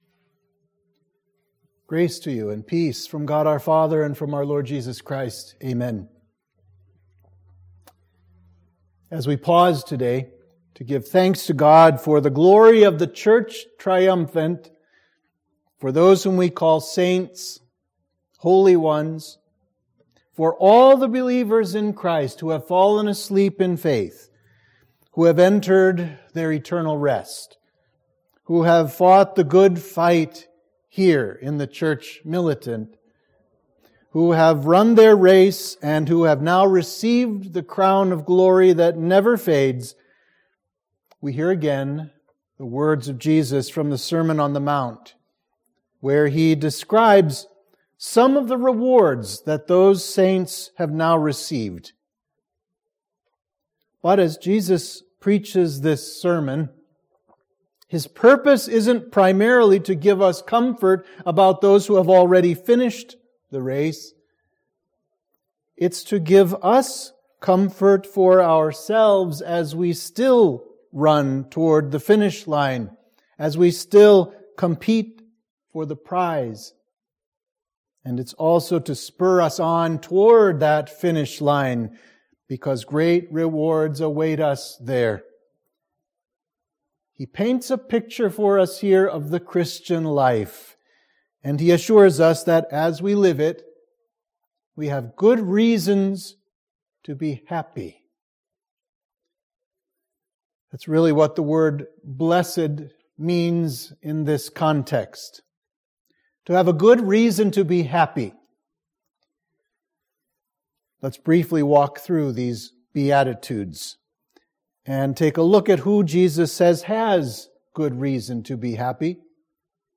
Sermon for the Festival of All Saints